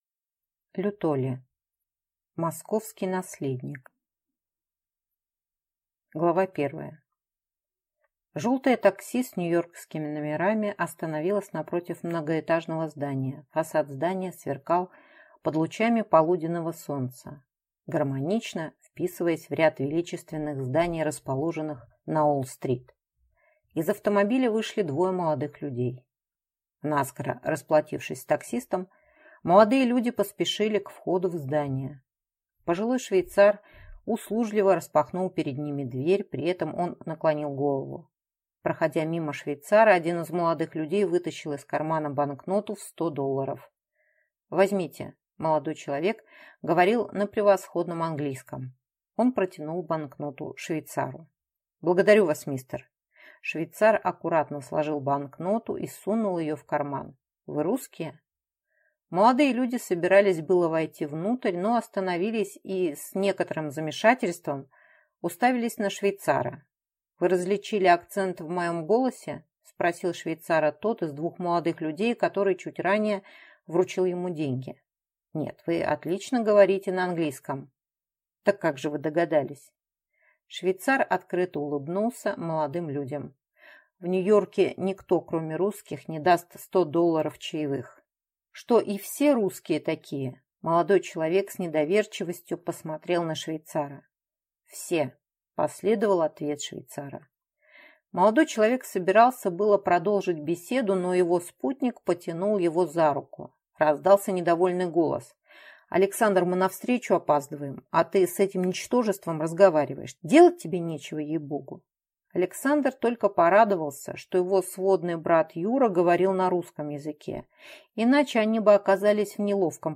Аудиокнига Московский наследник | Библиотека аудиокниг